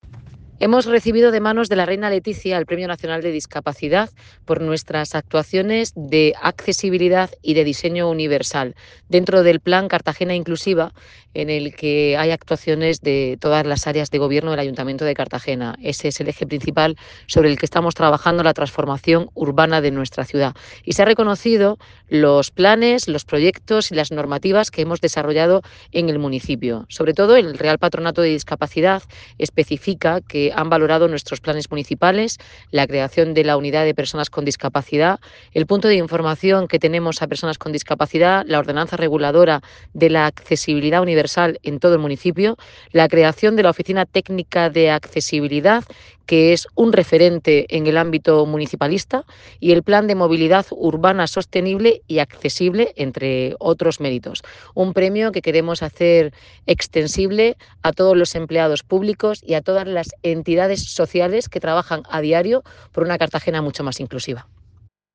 Enlace a Declaraciones de la alcaldesa, Noelia Arroyo, sobre Premio Nacional de Discapacidad Reina Letizia